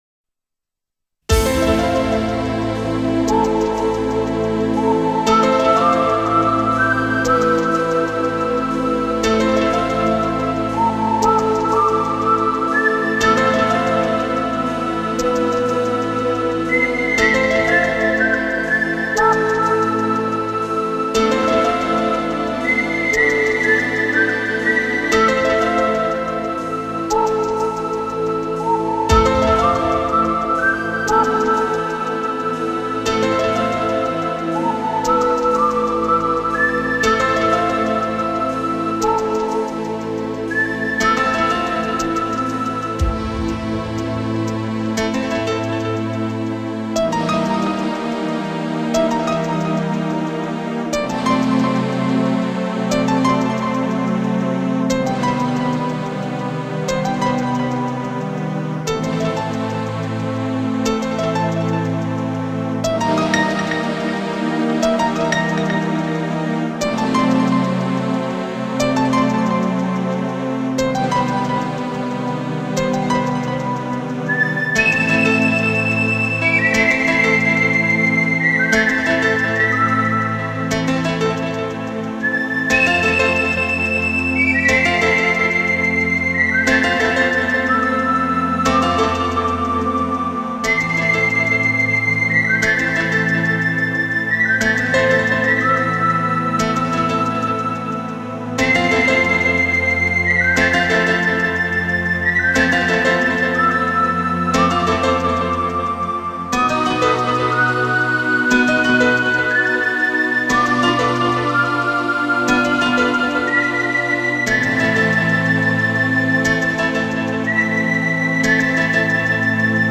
绝世好MUSIC--梦幻感觉，神秘色彩！～